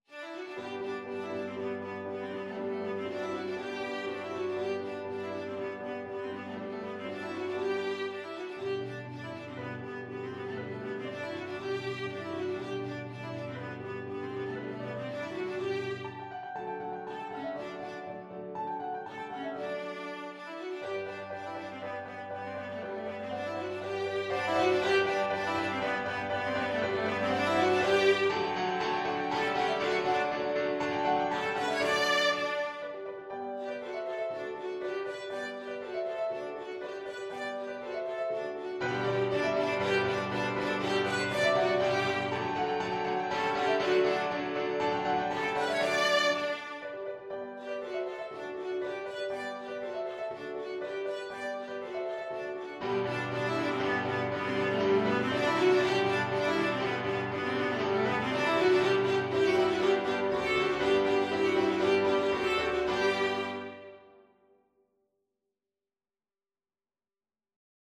2/4 (View more 2/4 Music)
Classical (View more Classical Viola Music)